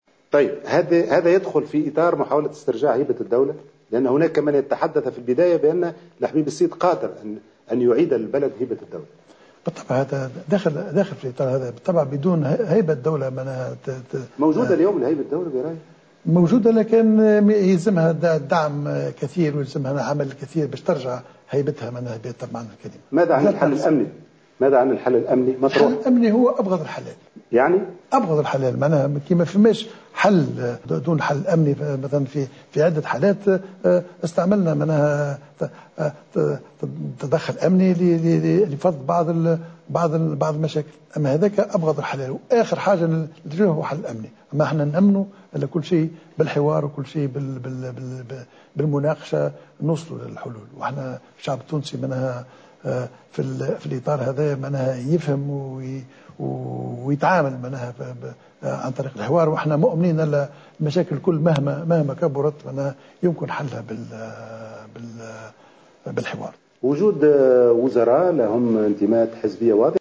وأضاف الحبيب الصيد في حوار حصري مع فرانس 24 أنه يؤمن بأن الحوار قادر على حل كل المشاكل الموجودة حاليا.